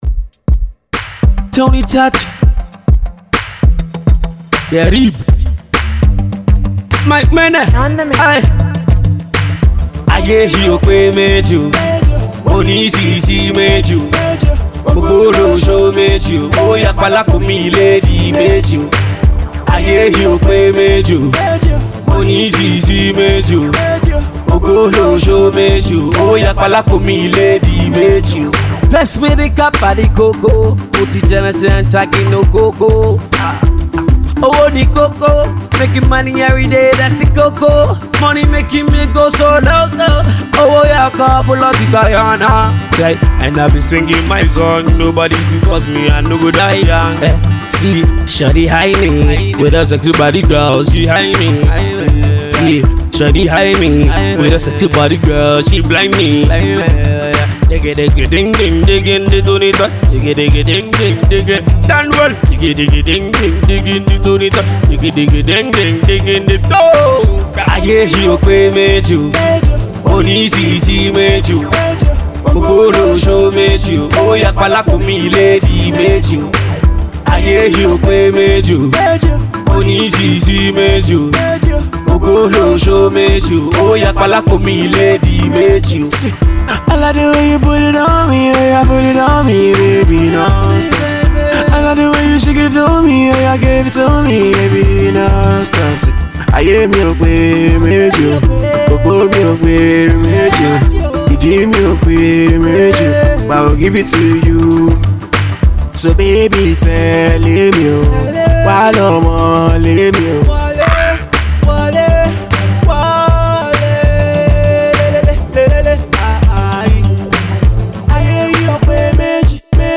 Pop …